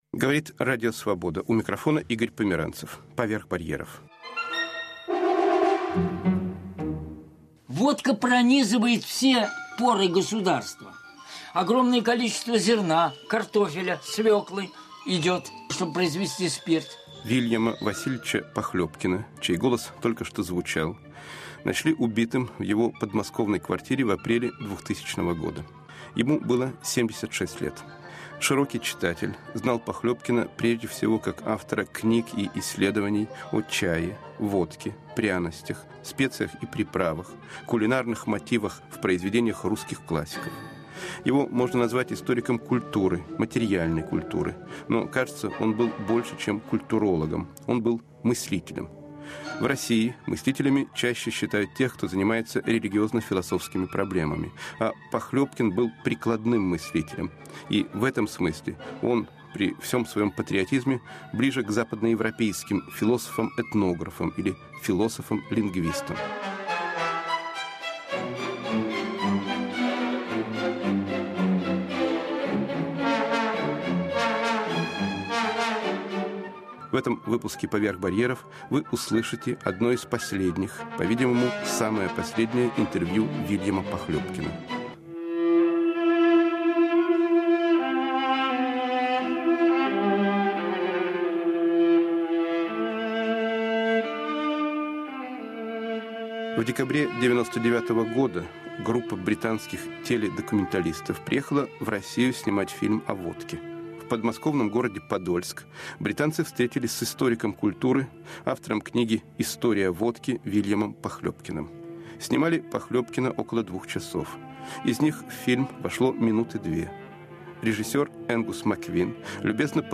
Вильям Похлёбкин. Последнее интервью